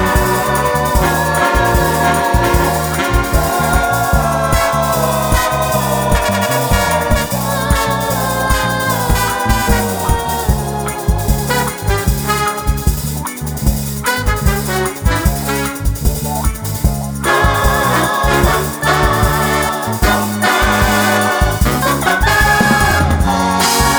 no Backing Vocals Crooners 2:30 Buy £1.50